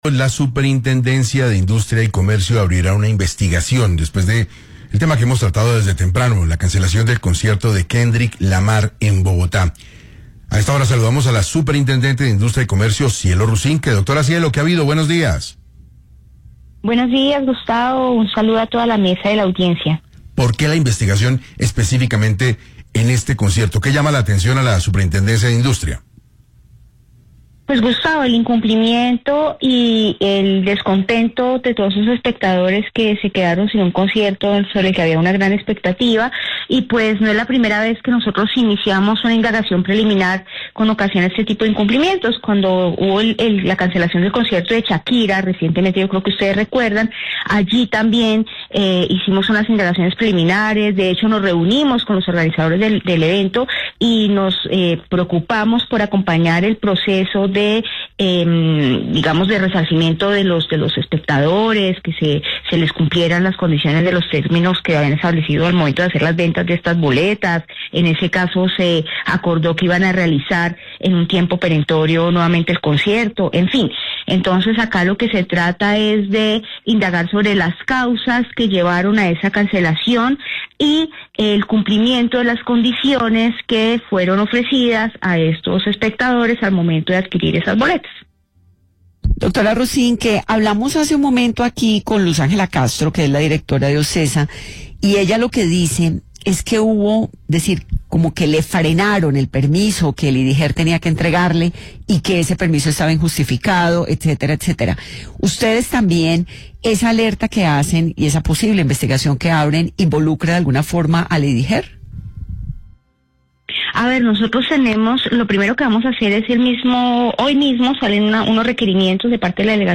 En entrevista con 6AM de Caracol Radio, la superintendente Cielo Rusinque aseguró que la entidad ya inició requerimientos preliminares para indagar sobre las causas de la cancelación y el cumplimiento de las condiciones ofrecidas a quienes compraron boletas.